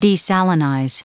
Prononciation du mot desalinize en anglais (fichier audio)
Prononciation du mot : desalinize